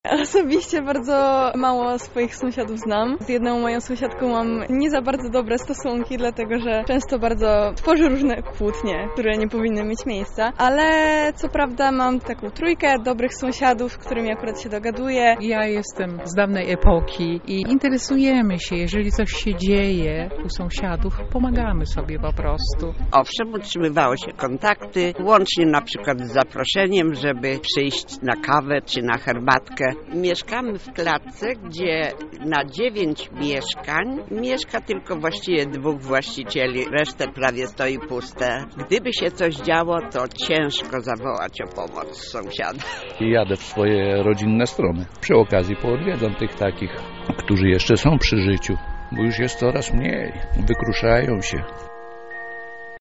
Nasza reporterka podpytała mieszkańców Lublina, jak te stosunki układają się w ich najbliższym otoczeniu: